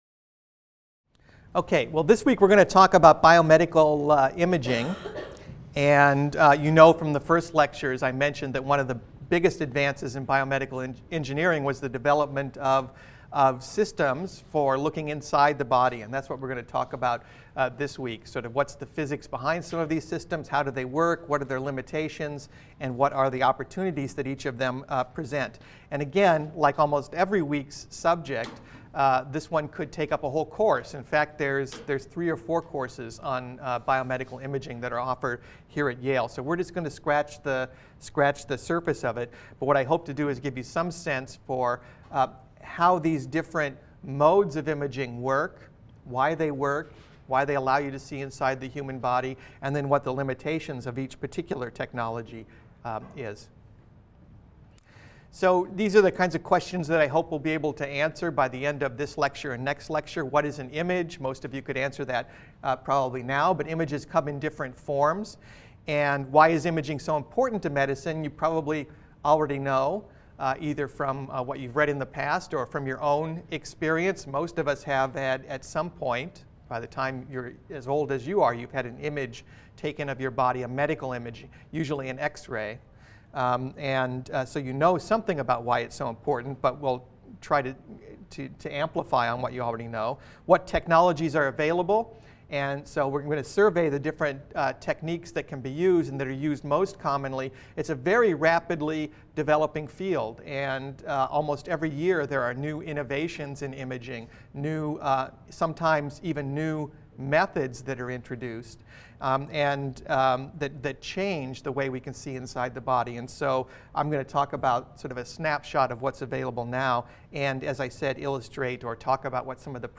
BENG 100 - Lecture 20 - Bioimaging | Open Yale Courses